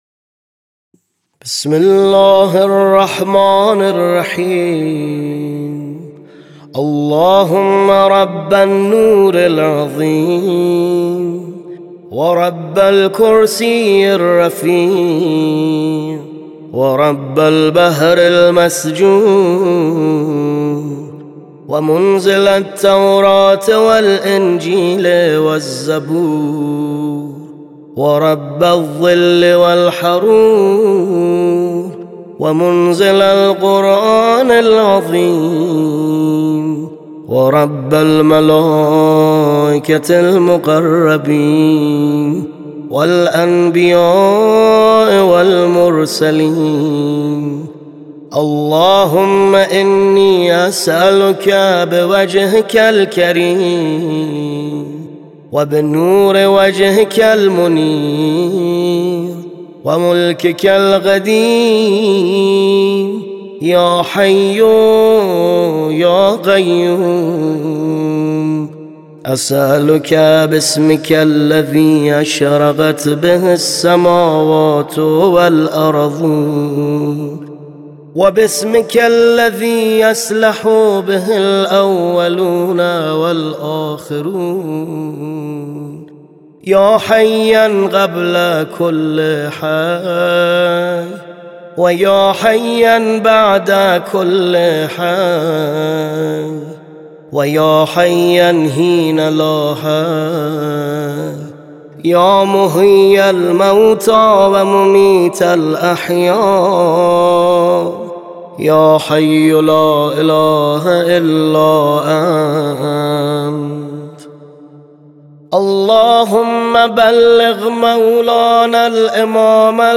تولید شده در استودیو واحد مهدویت مصاف (مهدیاران) به مناسبت تحویل سال ۱۴۰۰